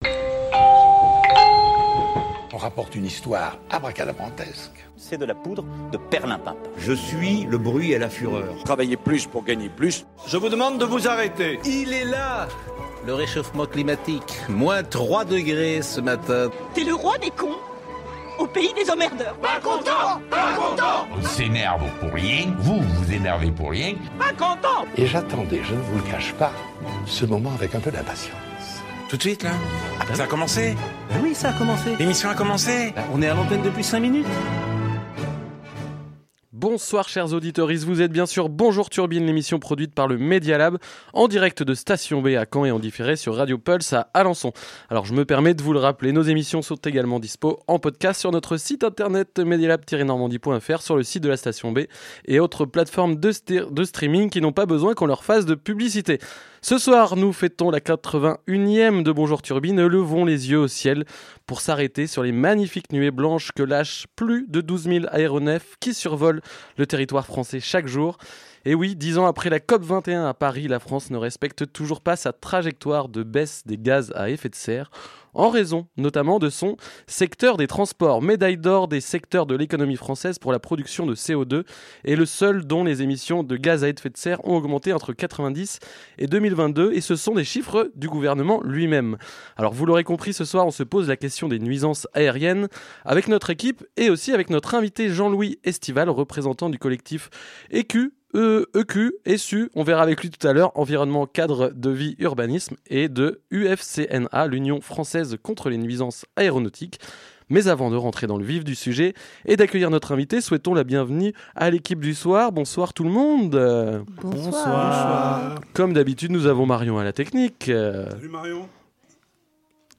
Radio Pulse 90.0FM à Alençon